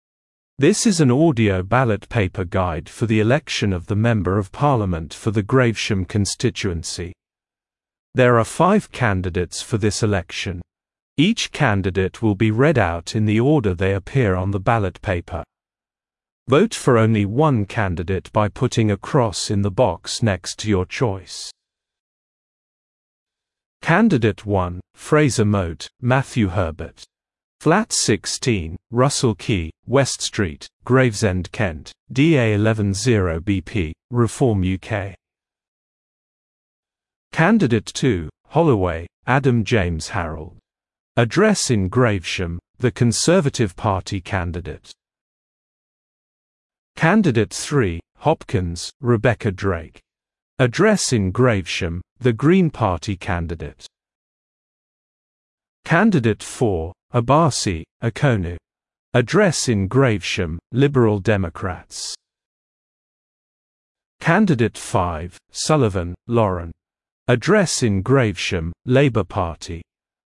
Audio ballot paper
Each candidate will be read out in the order they appear on your ballot paper.
audio-ballot-paper-uk-parliamentary-general-election-2024